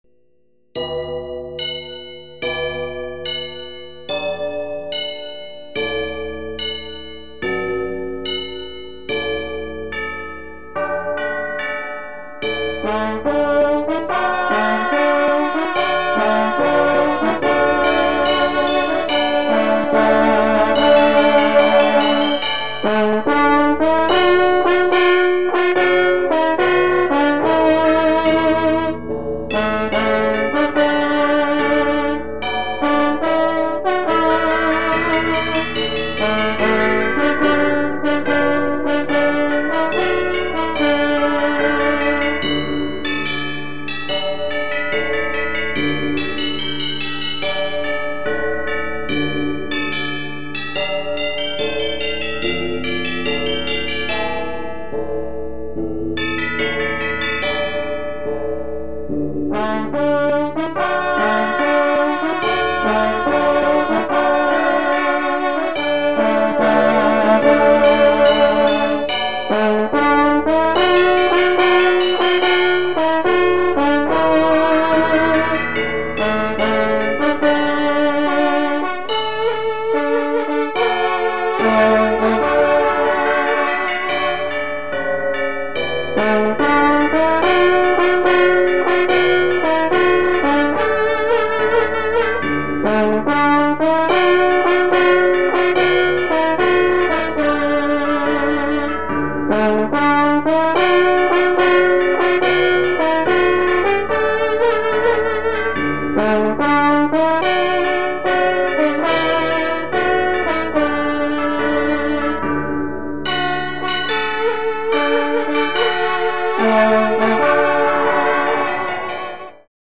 dans tous les enregistrements, le fond sonore est sur le canal gauche, la partie à travailler sur le canal droit !
Partie_A+B_chant_canal_droit.mp3